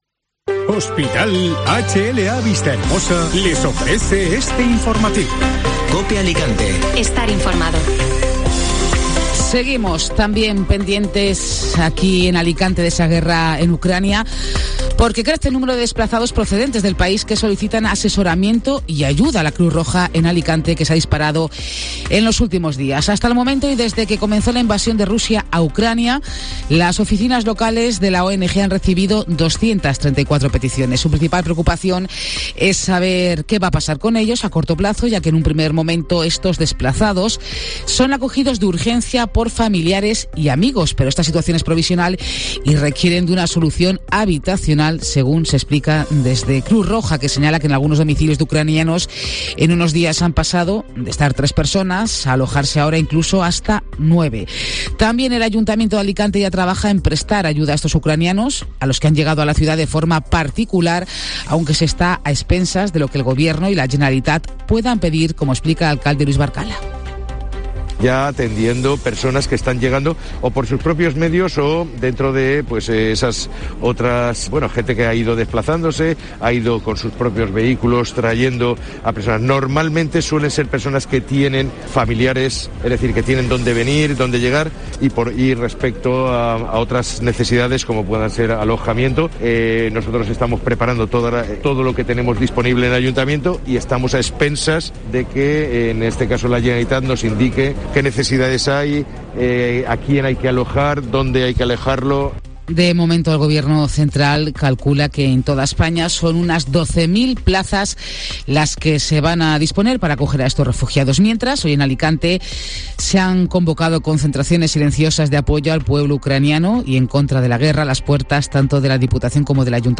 Informativo Mediodía COPE Alicante (Miércoles 9 de marzo)